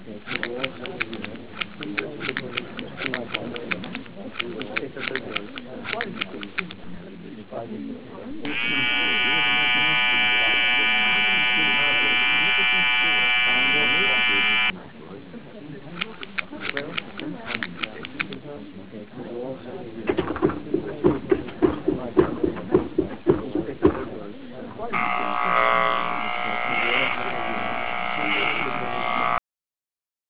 Barber Shop NECRHQ2024